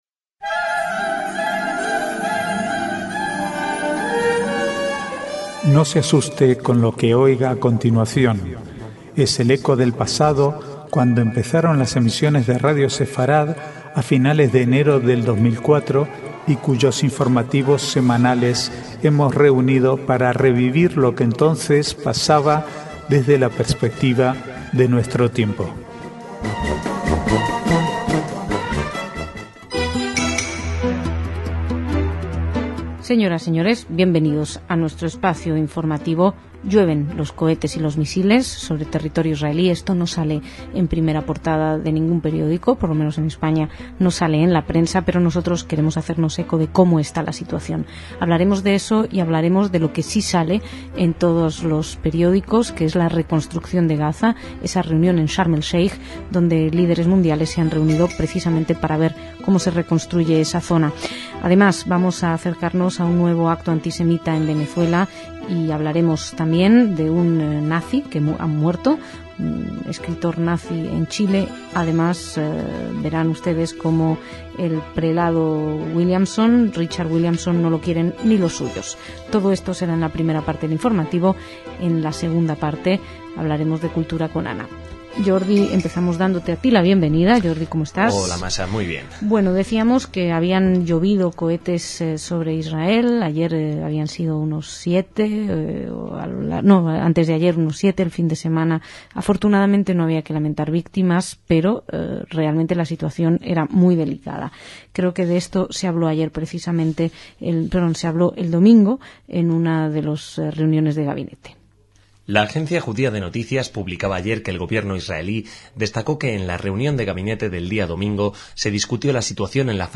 Archivo de noticias del 3 al 5/3/2009